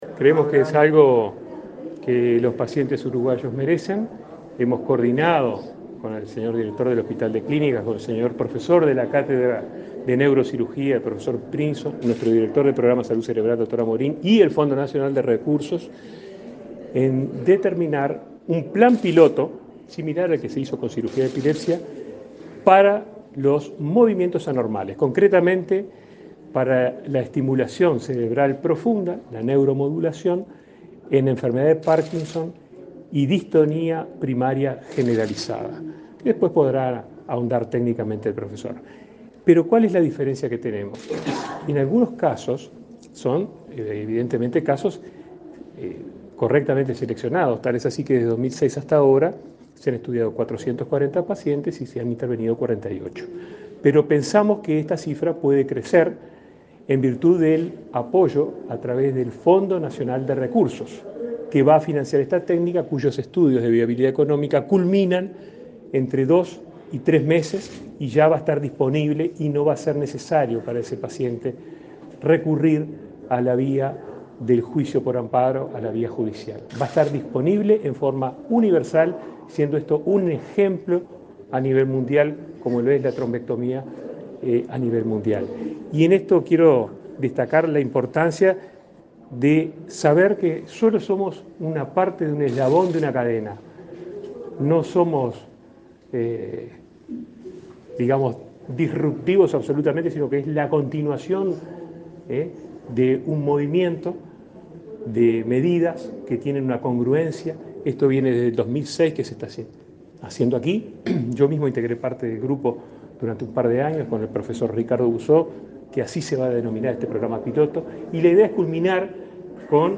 Declaraciones de Daniel Salinas y Álvaro Villar
El ministro de Salud Pública, Daniel Salinas, y el director del Hospital de Clínicas, Álvaro Villar, dialogaron con la prensa luego de la inauguración